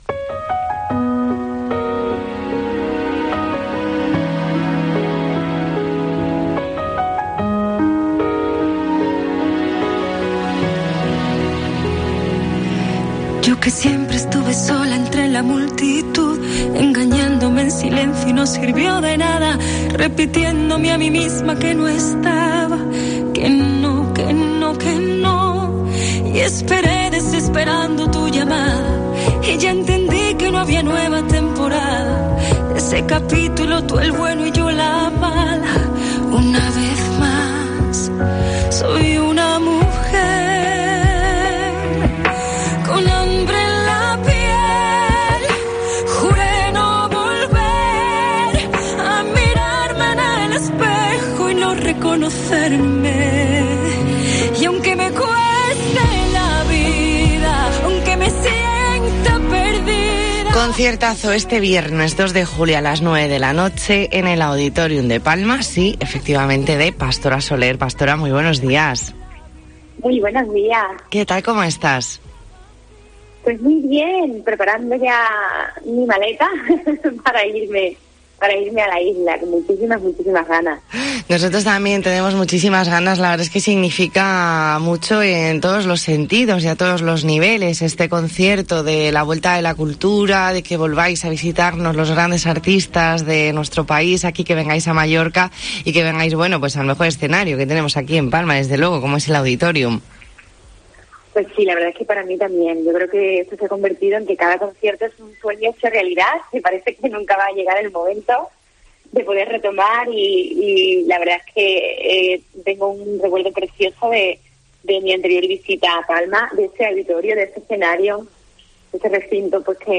Entrevista en La Mañana en COPE Más Mallorca, miércoles 30 de junio de 2021.